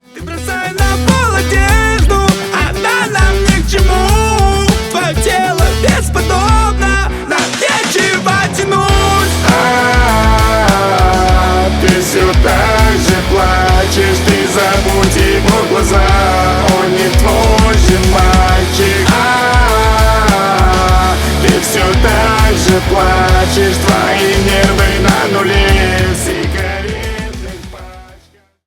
• Качество: 320 kbps, Stereo
Рок Металл
Рэп и Хип Хоп
грустные